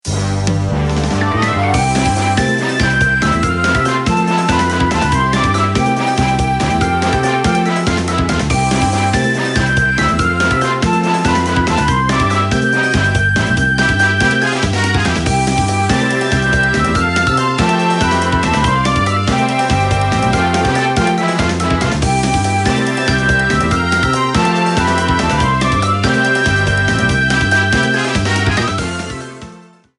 minigame music rearranged